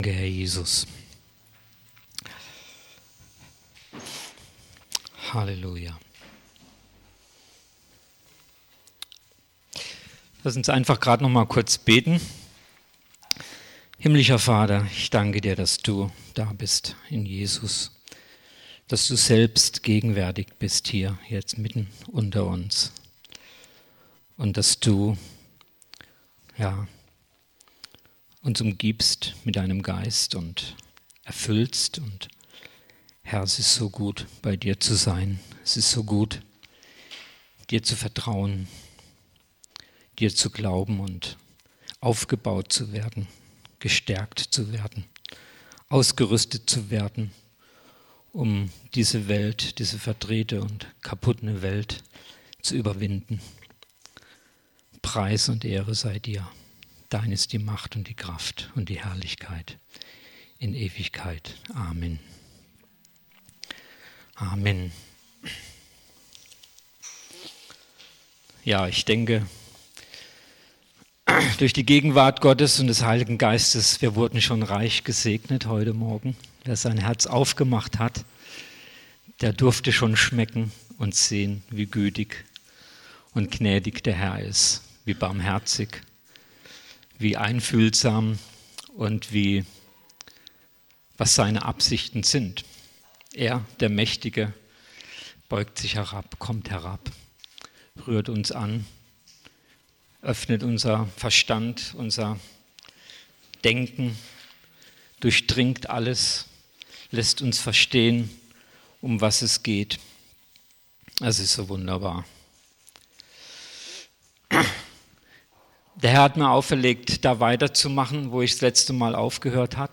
Predigt vom 27.10.2019